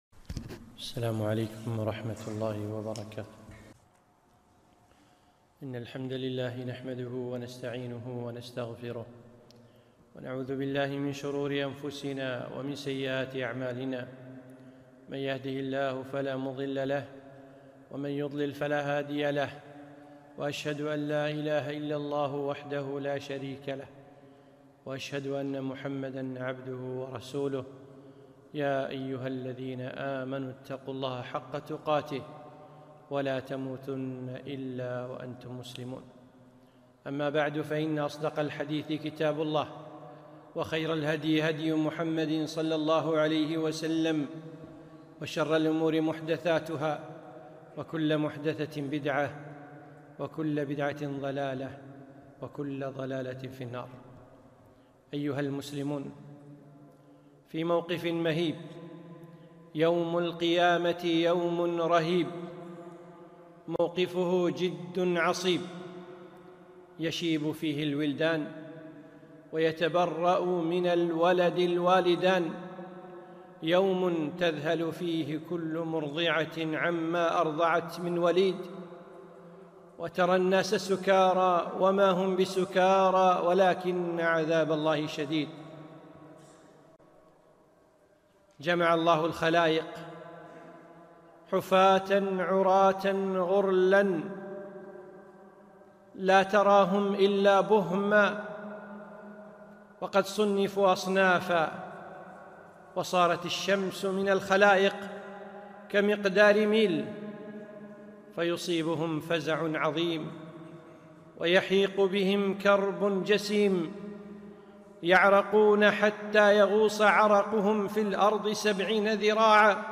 خطبة - أين أنت